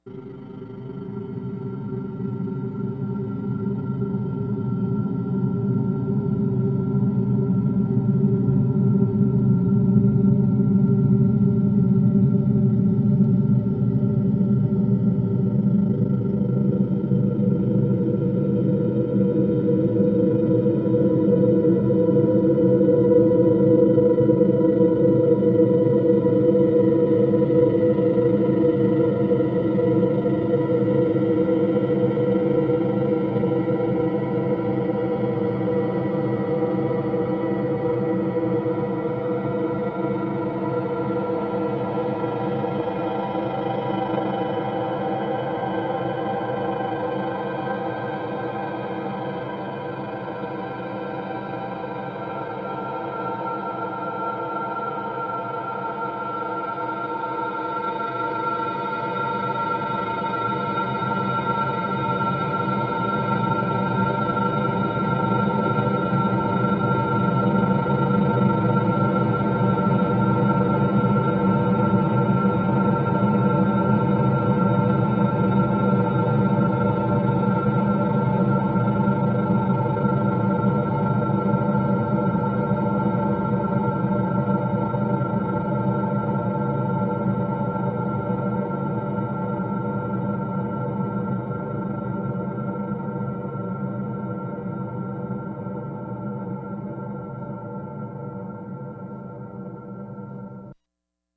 Source: Resonated harmonic gliss on C (4:55-6:37)
Processing: granulated, amp. correlated at max.50:1 + 5th higher